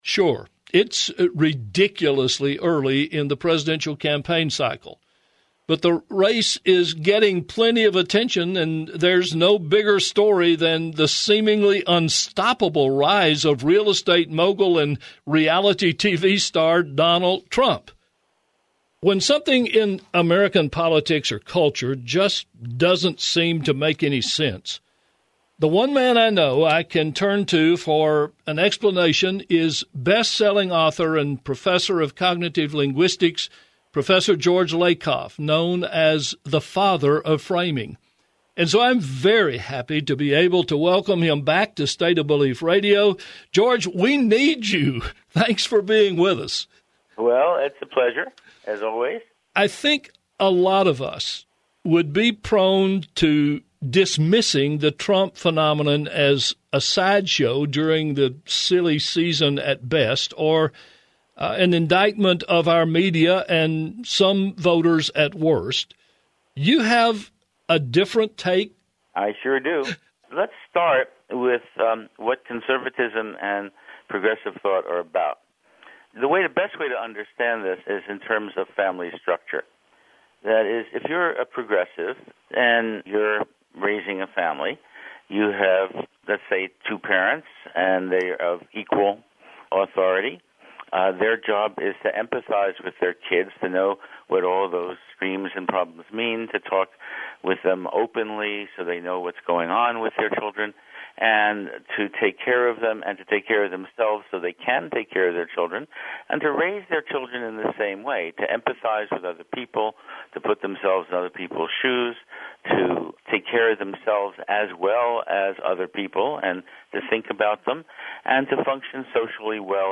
Well, we don’t understand what’s going on with Donald Trump (Rasmussen just released a new survey showing 57% of likely GOP voters expect The Donald to be their nominee!), so we’re inviting Prof. Lakoff on this week to see what he has to say. He’s also got an exciting new training program gearing up to engage progressives in refining their messaging skills, called Reframe America.